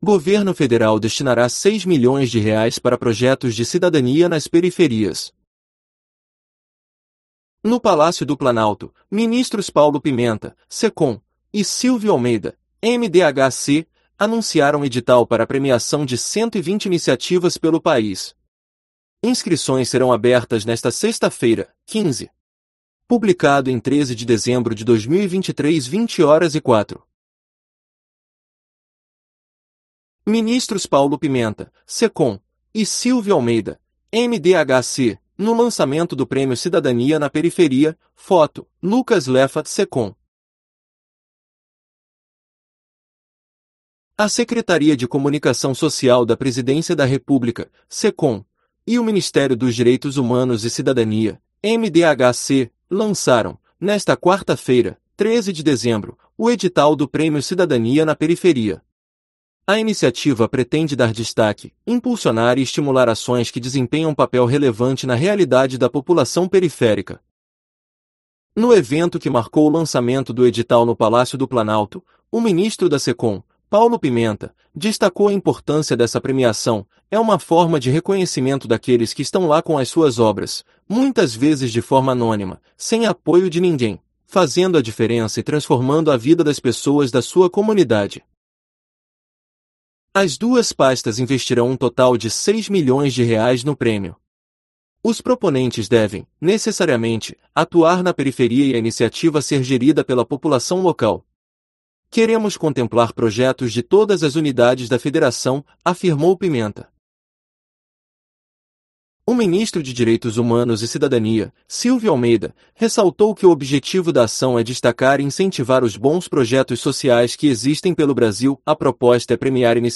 No Palácio do Planalto, ministros Paulo Pimenta (SECOM) e Silvio Almeida (MDHC) anunciaram edital para premiação de 120 iniciativas pelo país.